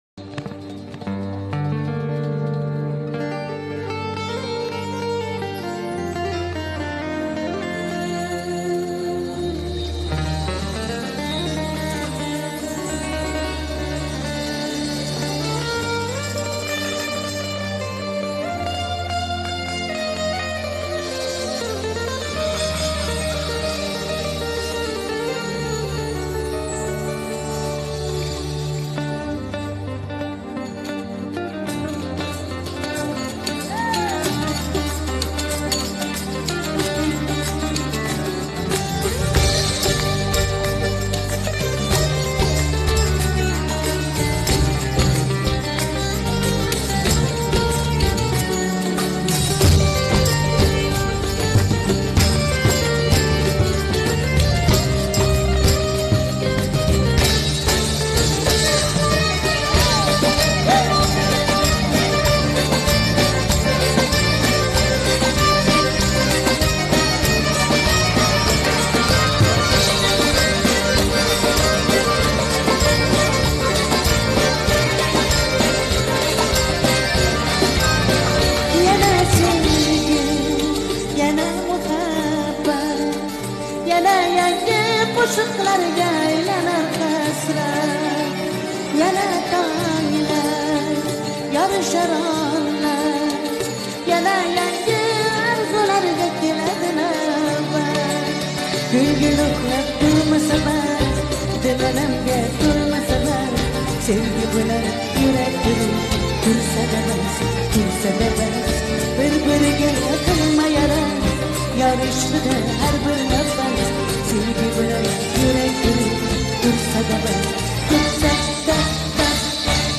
в прямом эфире
consert version